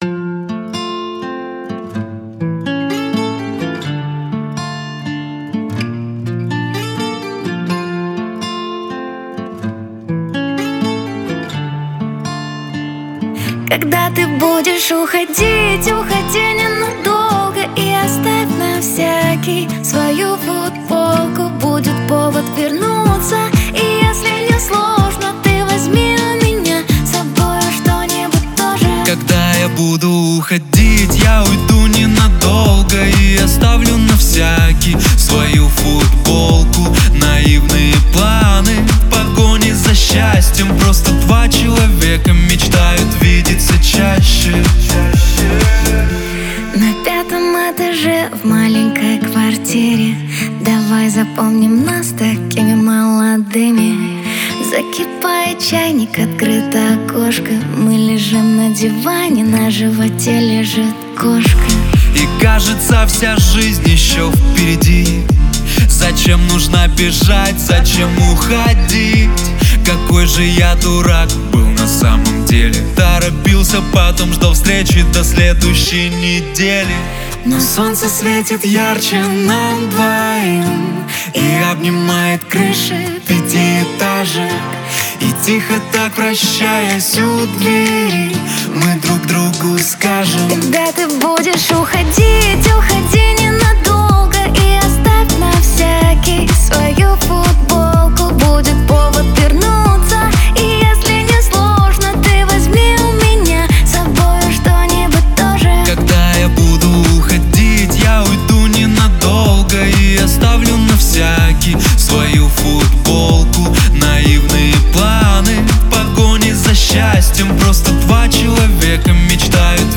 Жанр: Русские народные песни